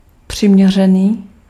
Ääntäminen
IPA: /ʁɛ.zɔ.nabl/